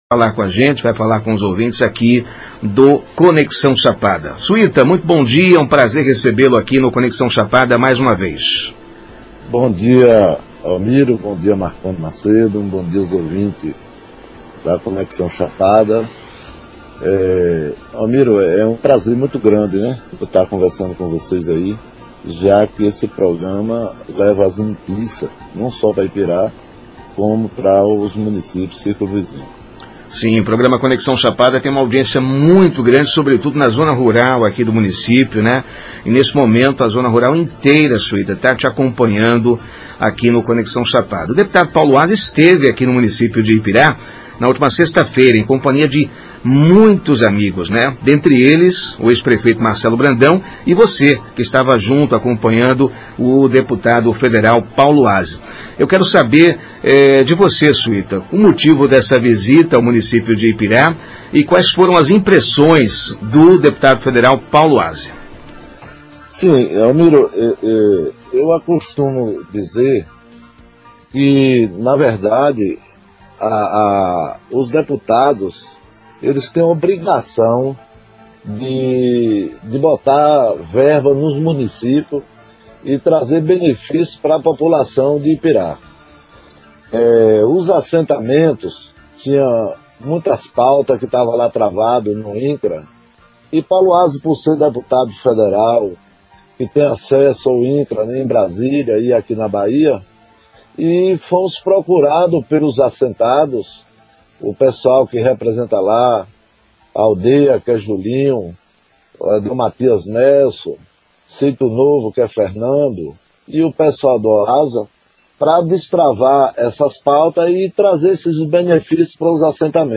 ENTREVISTA-COM-VEREADOR-SUITA..mp3